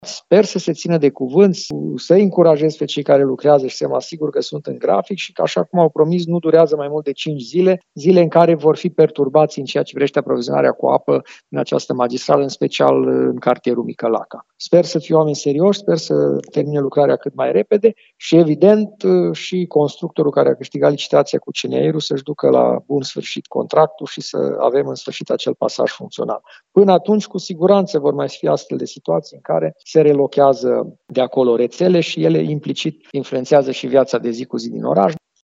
Lucrările sunt programate să se încheie pe 30 ianuarie, spune primarul Călin Bibarț.